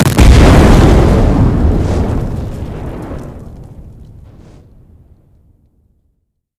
nuclear-explosion-3.ogg